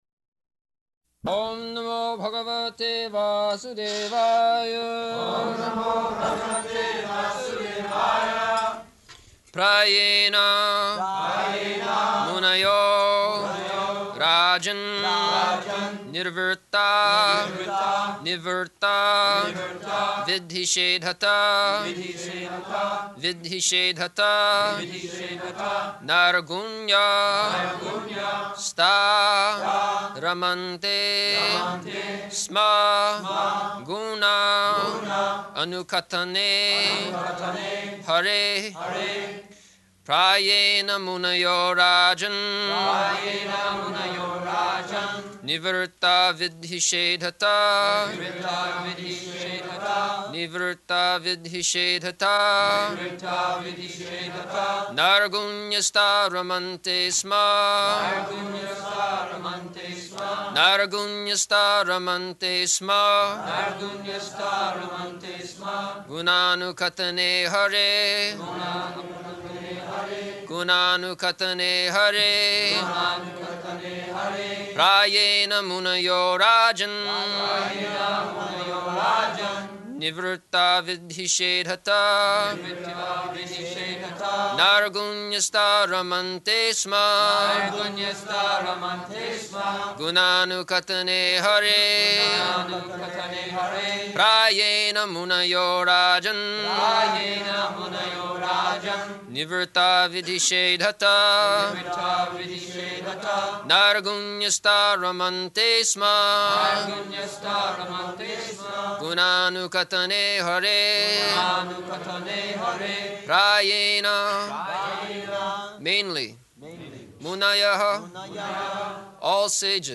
June 15th 1974 Location: Paris Audio file